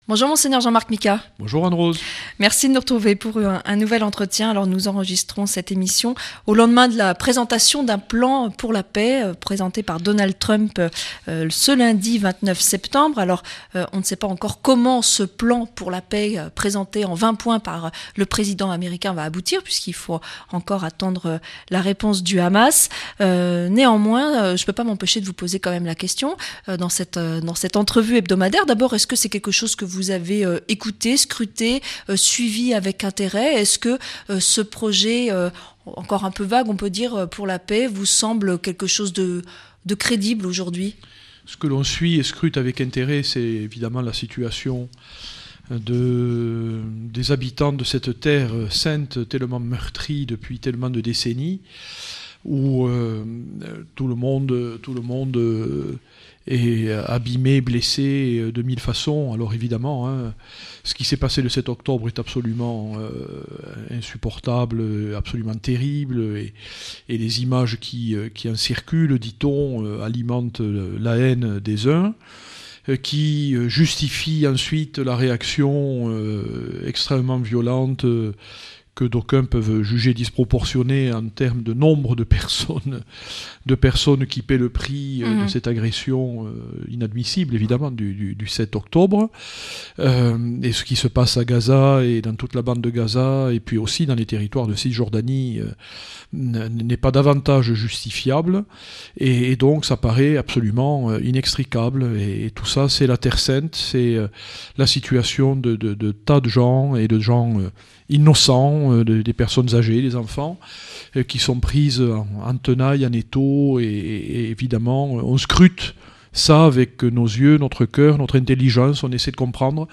Entretien avec Mgr Micas - Évêque de Tarbes Lourdes